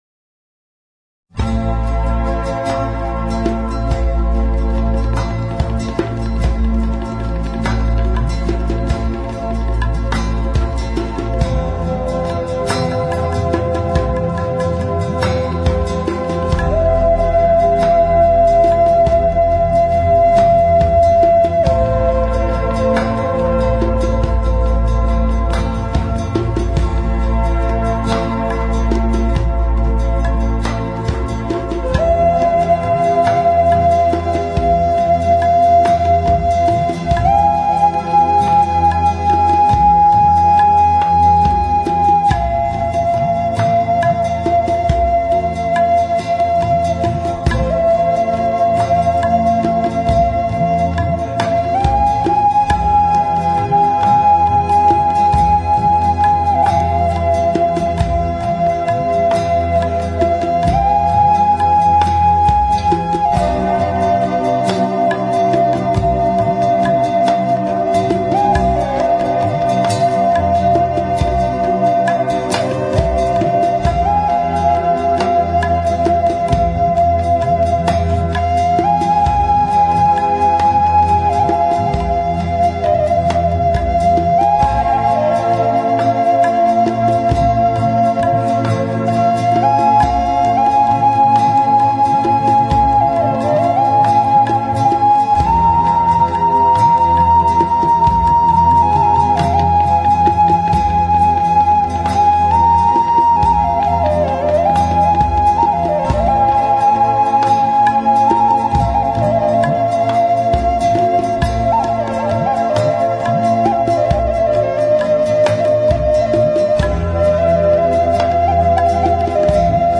Музыка шаманов